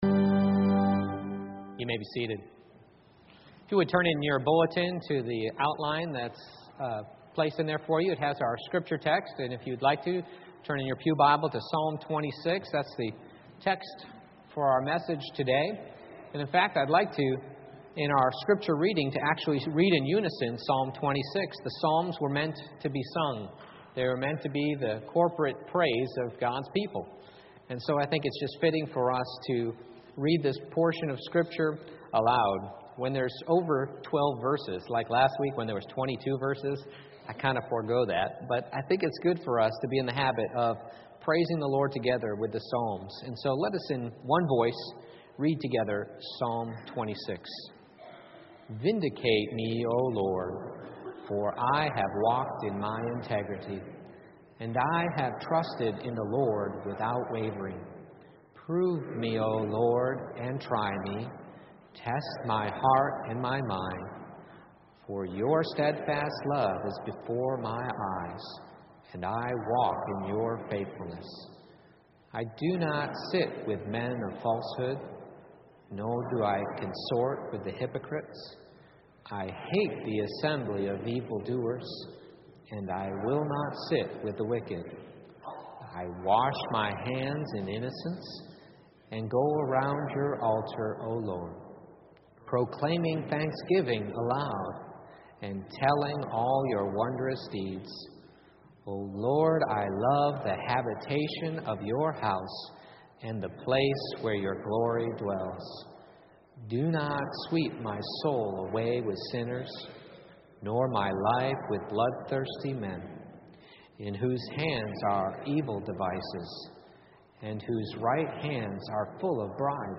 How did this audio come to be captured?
Passage: Psalm 26:1-12 Service Type: Morning Worship